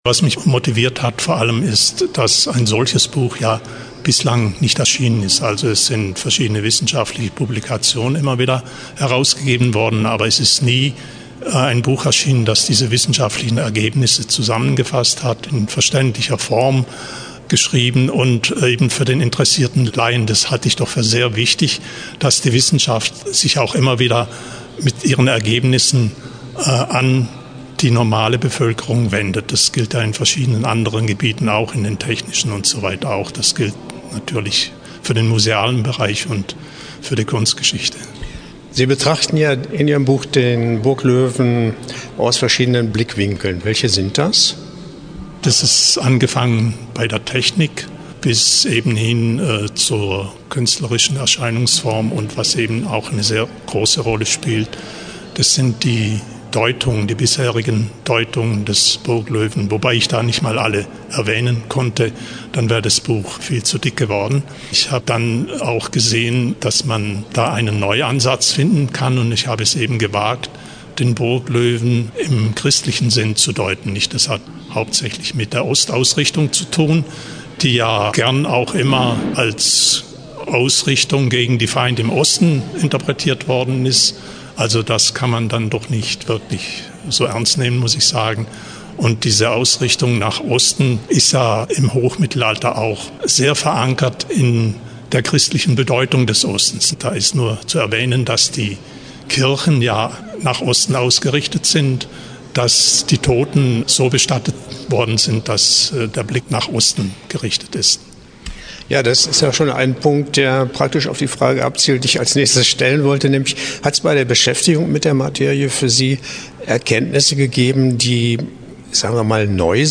Interview-Burgloewe-Buch.mp3